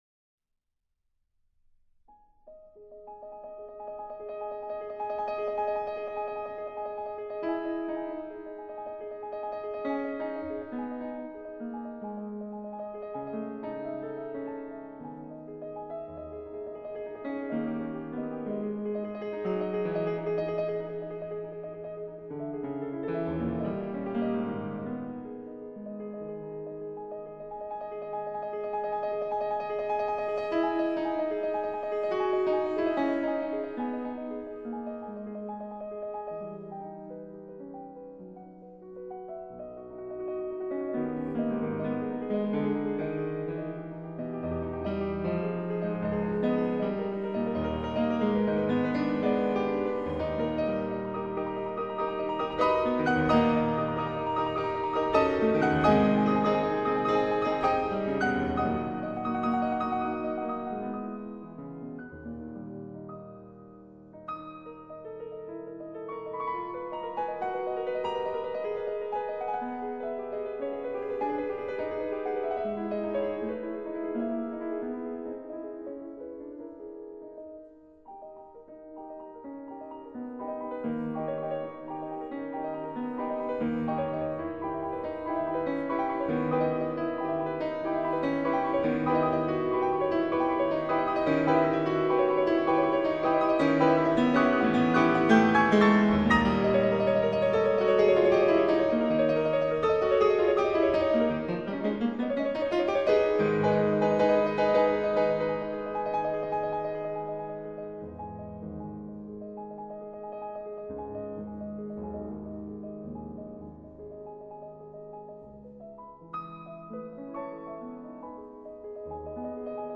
专辑类别：古典音乐1CD